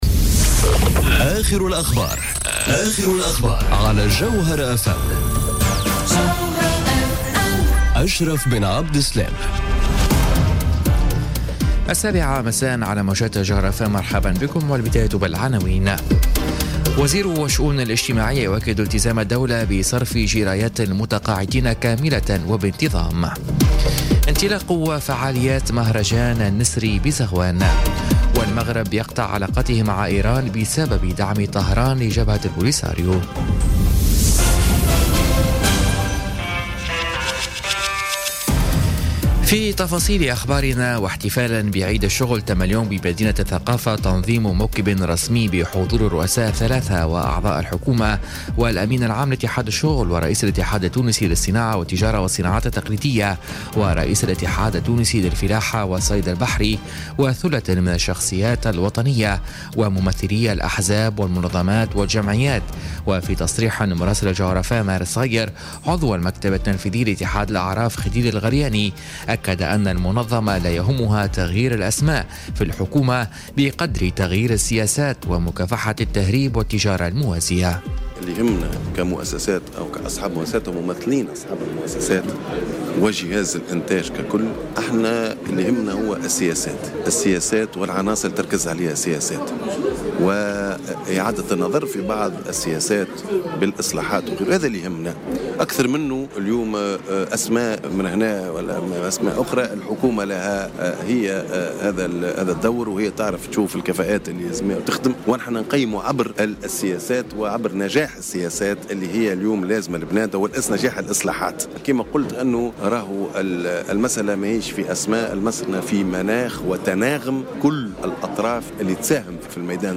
نشرة أخبار السابعة مساء ليوم الثلاثاء غرة ماي 2018